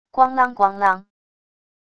咣啷咣啷wav音频